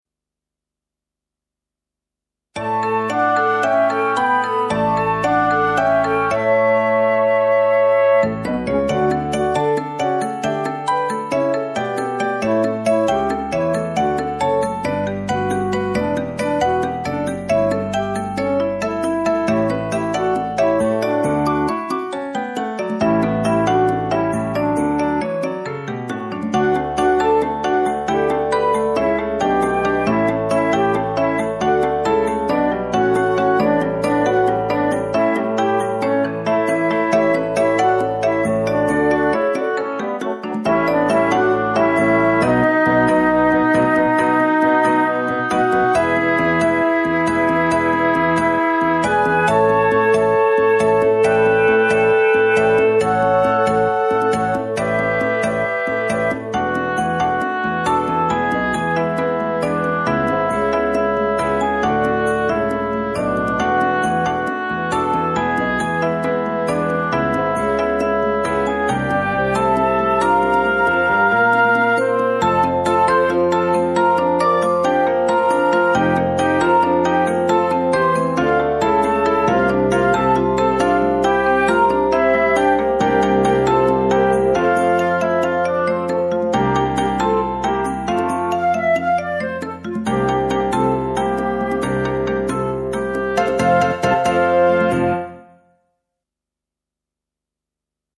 Vocal with Piano
Songs can be sung in unison or split into two
The album is produce for piano and voice.
sparkling backing / practice tracks.